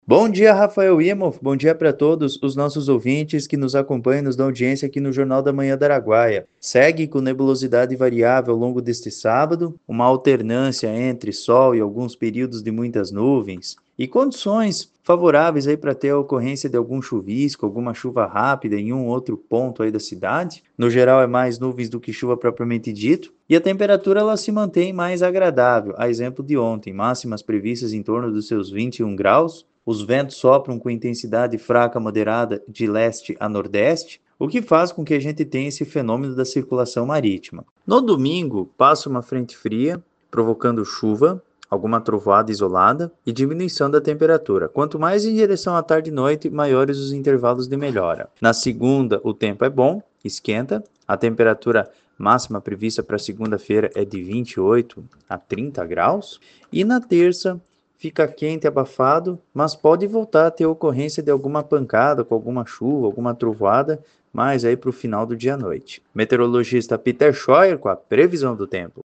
PREVISAO-DO-TEMPO-27-09-JMANHA.mp3